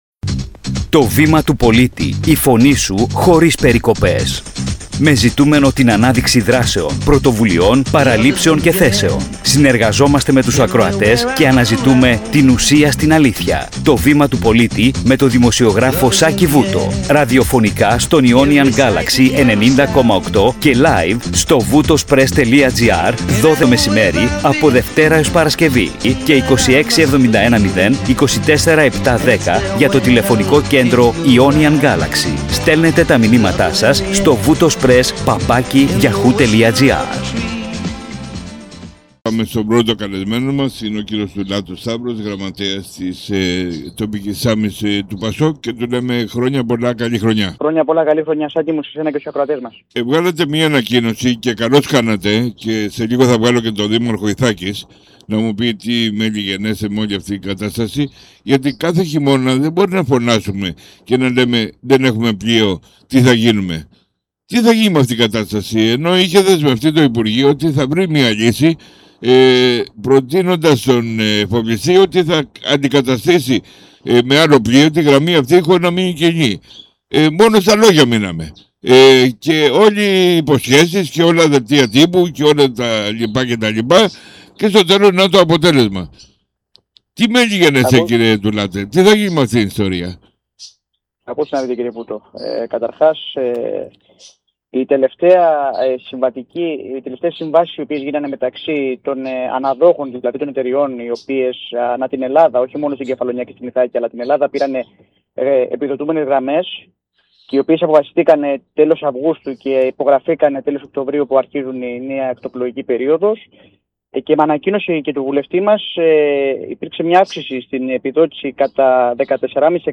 Σε μια ιδιαίτερα αιχμηρή συζήτηση